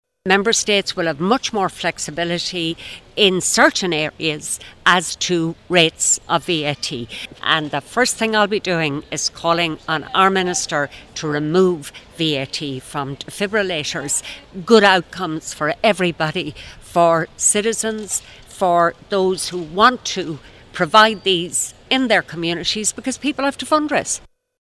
Independent MEP for Midlands North-West, Marian Harkin, wants the Government to now remove VAT from defibrillators: